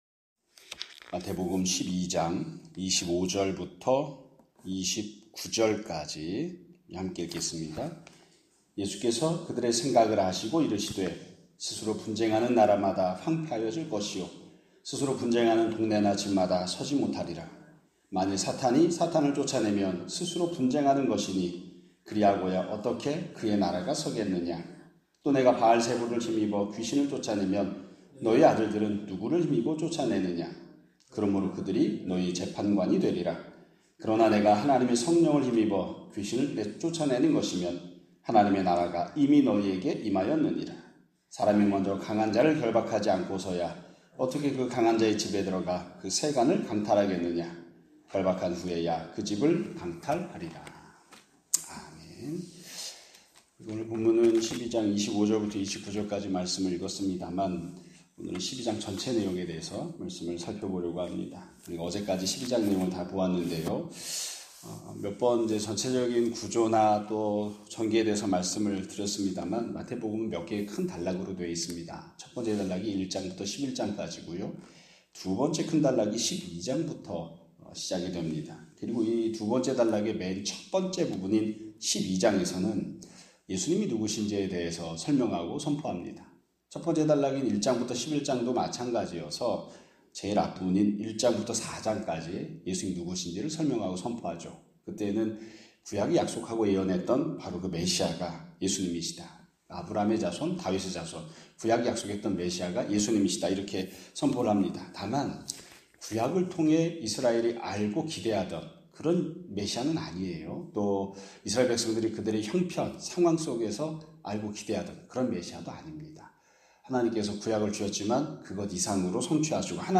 2025년 9월 23일 (화요일) <아침예배> 설교입니다.